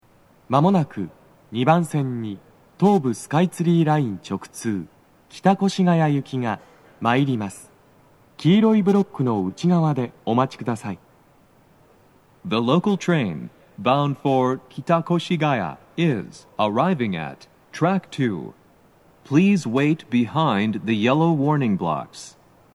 スピーカー種類 BOSE天井型
鳴動は、やや遅めです。
接近放送 【男声